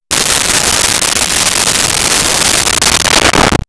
Monster Synth Snarl 01
Monster Synth Snarl 01.wav